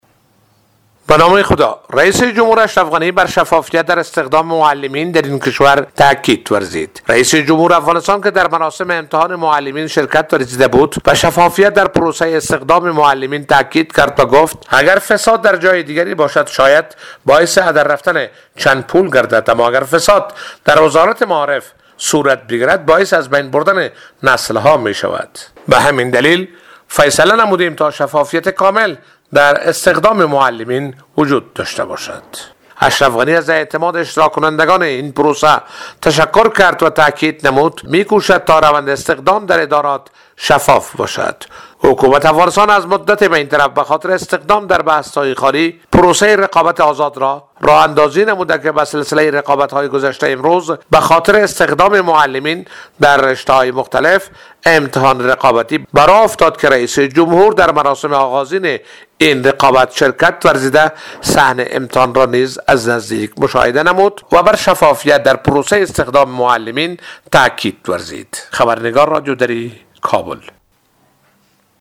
گزارش : تاکید اشرف غنی بر شفافیت پروسه استخدام معلمان